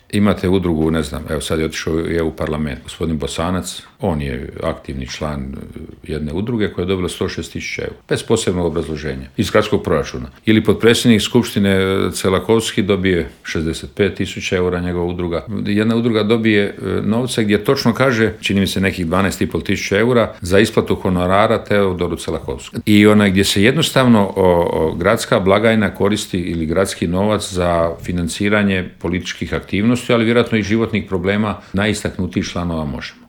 ZAGREB - Predsjednik stranke Plavi Grad, zastupnik u Gradskoj skupštini i kandidat za gradonačelnika Grada Zagreba Ivica Lovrić u Intervjuu Media servisa osvrnuo se na na ključne gradske probleme poput opskrbe plinom, Jakuševca i prometnog kolapsa.